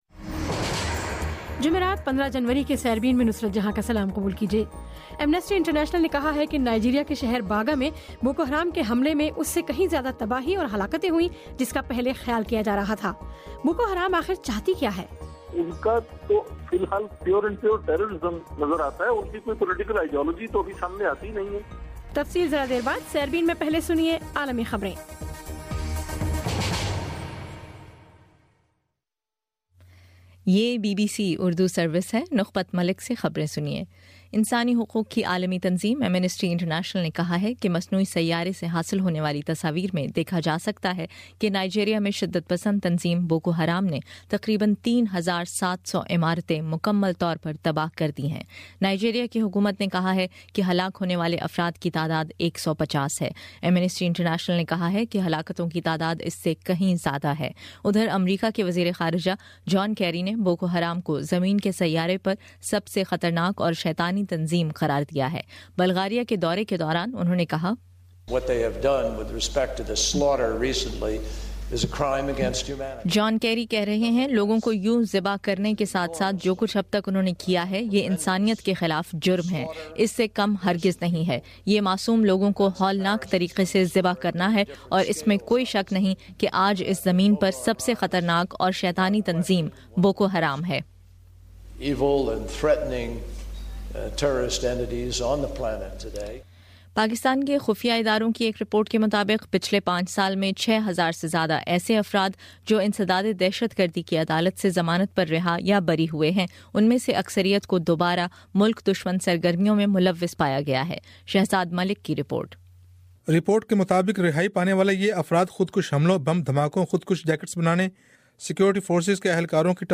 جمعرات 15 جنوری کا سیربین ریڈیو پروگرام